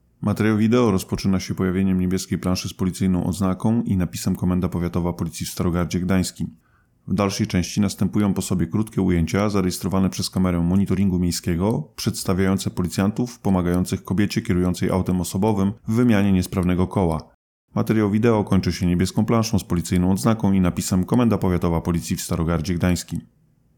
Nagranie audio Audio deskrypcja do materiału wideo.